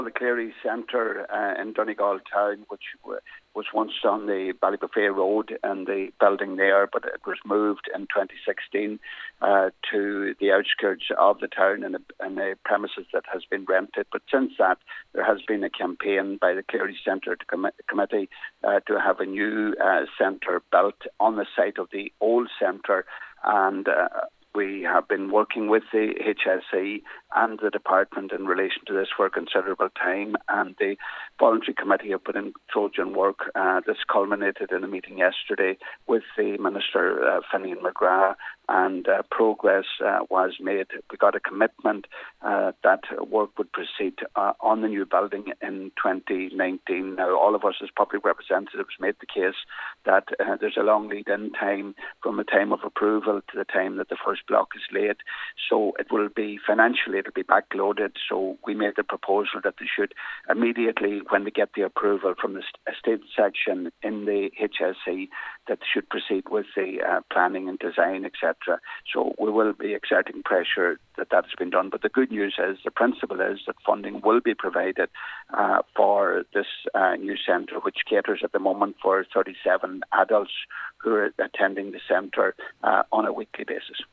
Speaking on the Nine til Noon Show earlier today, Leas Cheann Comhairle and Donegal Deputy Pat the Cope Gallagher says it is important there are no delays in progressing the project: